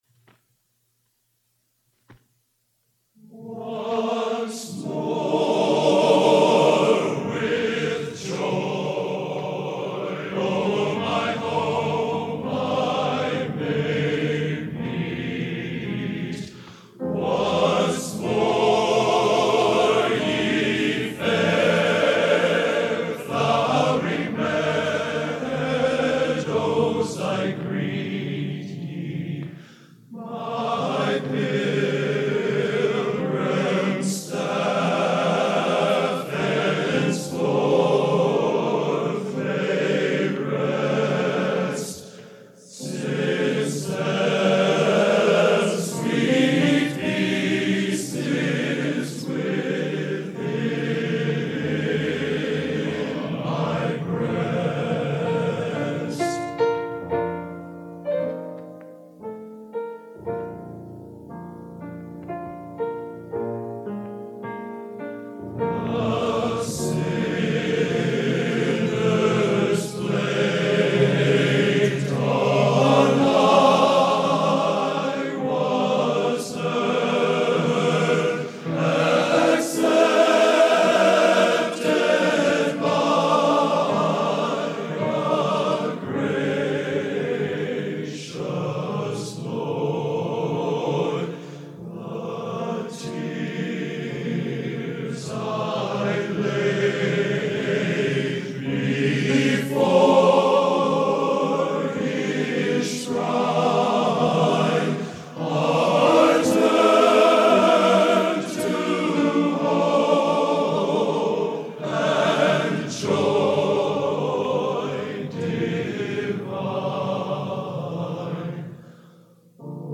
Genre: Classical Opera | Type: Studio Recording